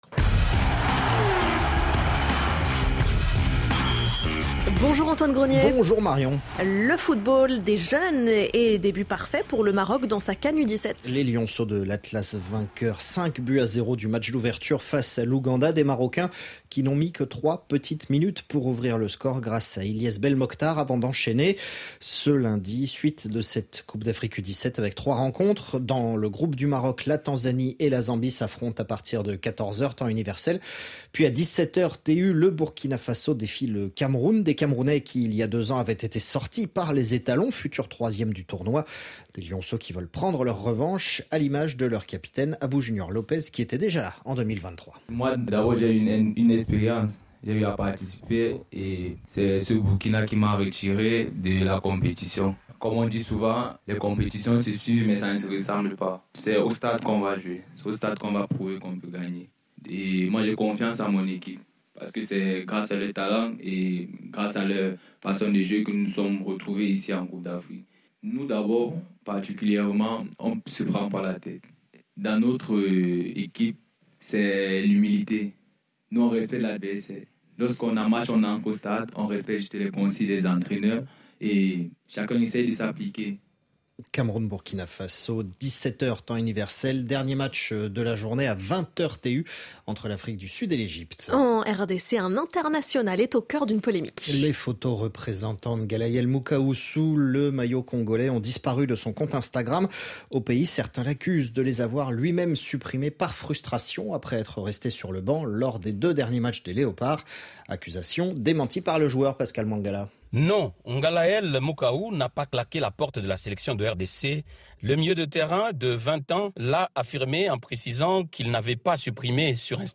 En 4 minutes chaque jour, toute l'actualité sportive du continent africain et des sportifs africains dans le monde. Présenté par le Service des Sports de RFI.